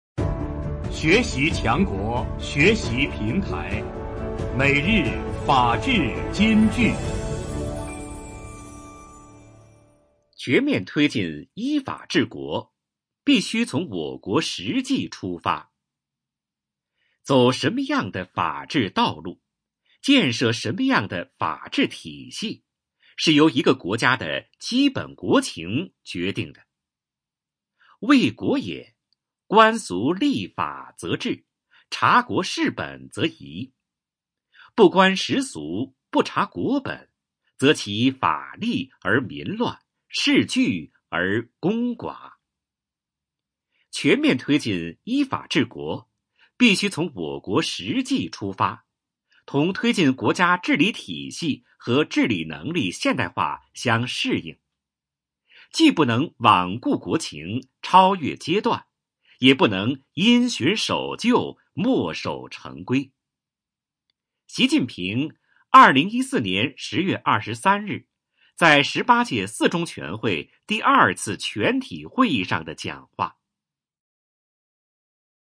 每日法治金句（朗读版）|全面推进依法治国，必须从我国实际出发 _ 学习宣传 _ 福建省民政厅